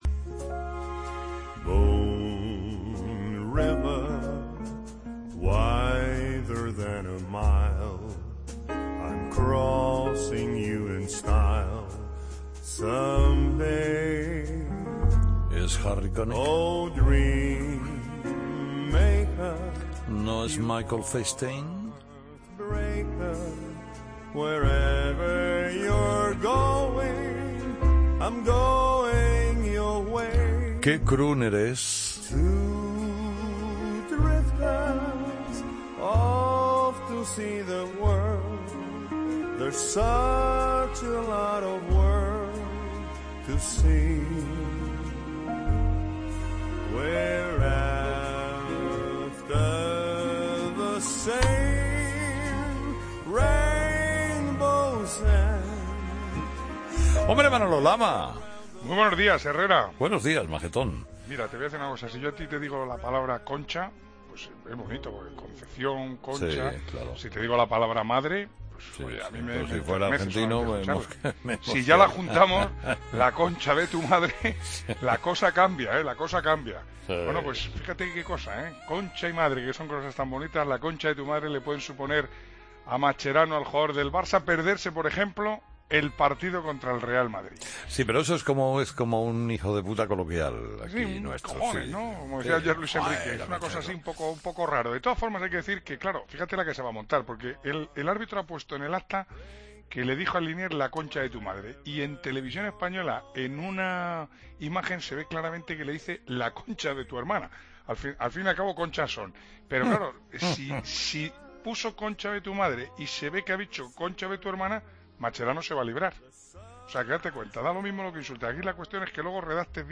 Redacción digital Madrid - Publicado el 26 oct 2015, 12:04 - Actualizado 13 mar 2023, 17:01 1 min lectura Descargar Facebook Twitter Whatsapp Telegram Enviar por email Copiar enlace El deporte con Manolo Lama. Ricardo Darín y Javier Cámara nos presentan su última película 'Truman'.